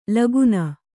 ♪ laguna